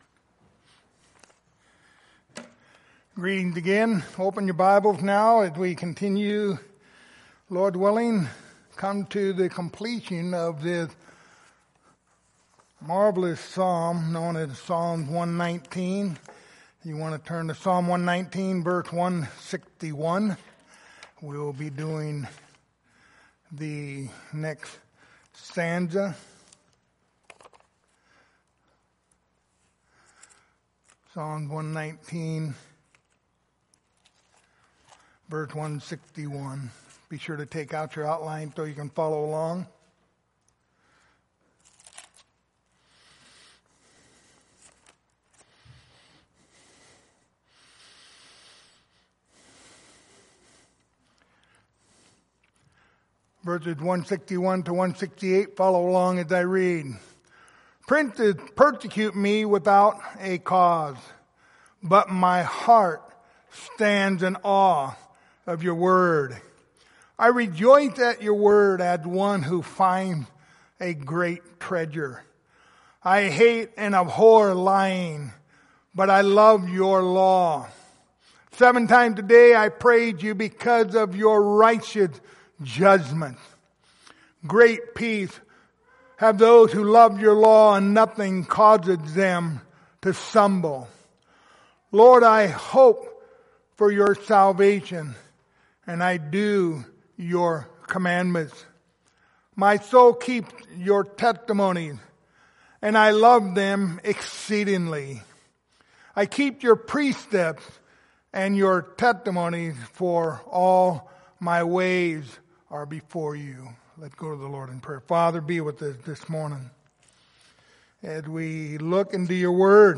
Passage: Psalms 119:161-168 Service Type: Sunday Morning